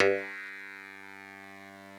genesis_bass_031.wav